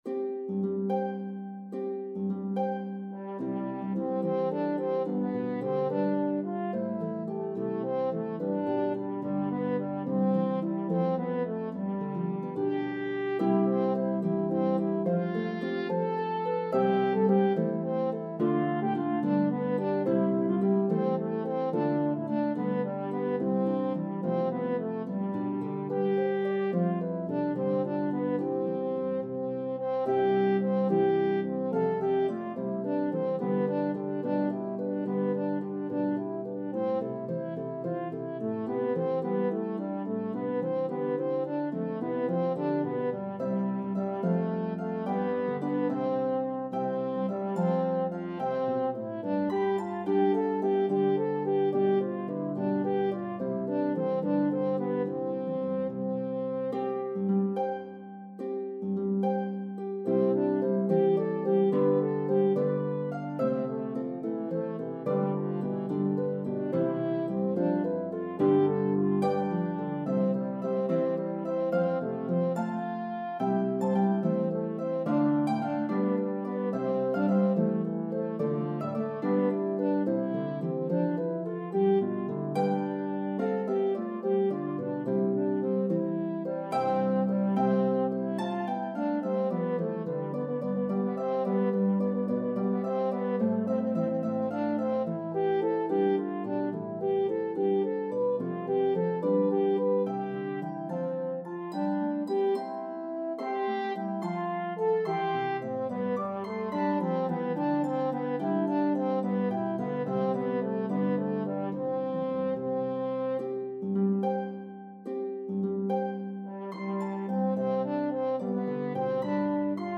The Harp part is playable on either Lever or Pedal Harps.